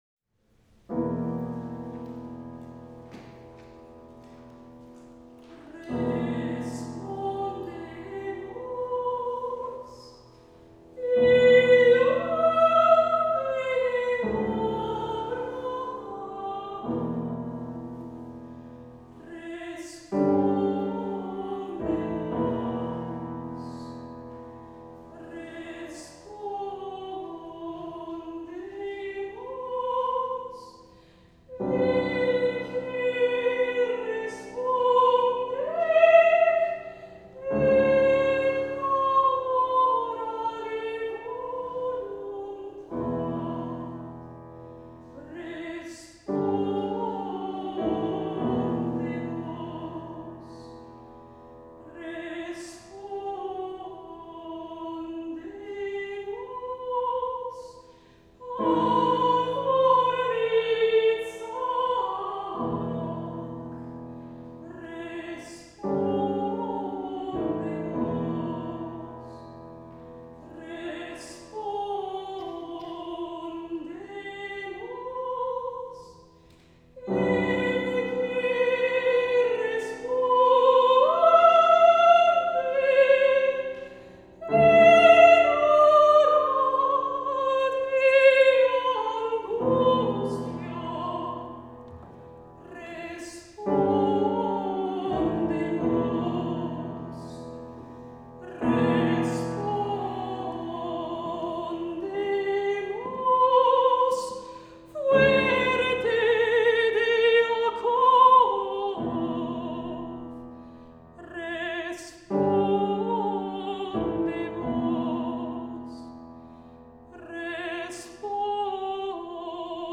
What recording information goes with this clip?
The recordings of these live performances are available for view.